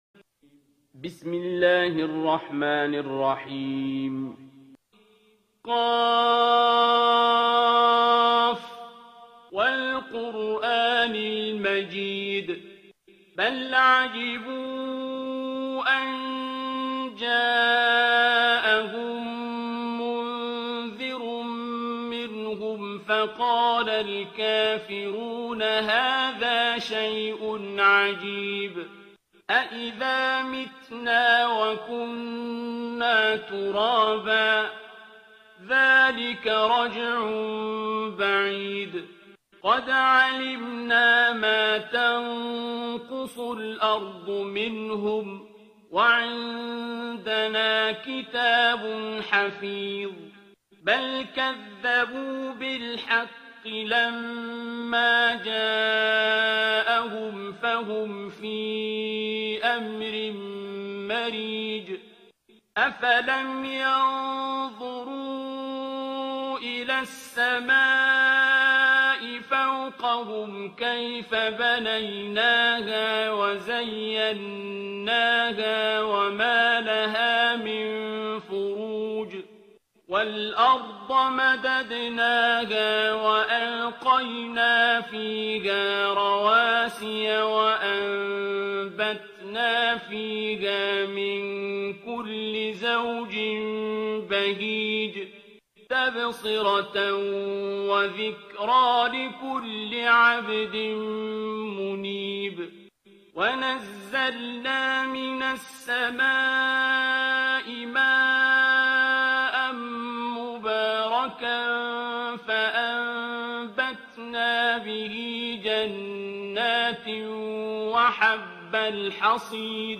ترتیل سوره ق با صدای عبدالباسط عبدالصمد
050-Abdul-Basit-Surah-Qaf.mp3